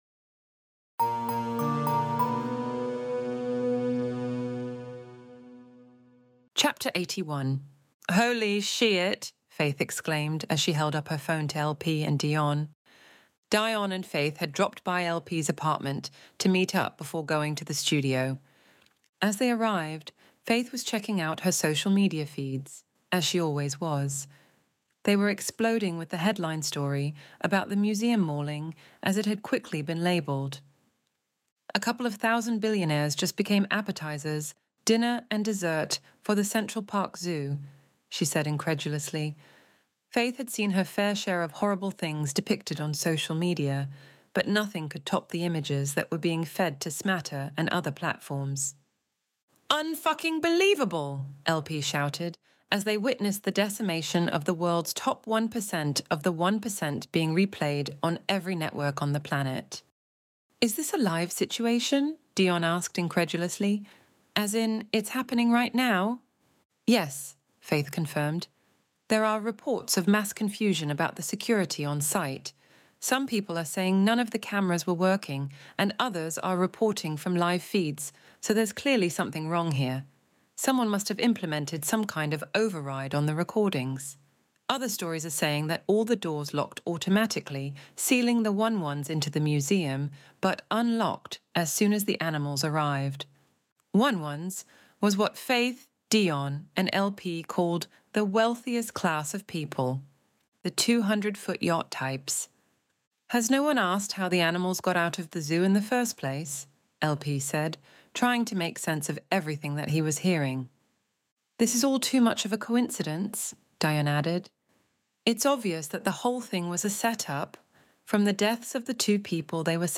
Extinction Event Audiobook Chapter 81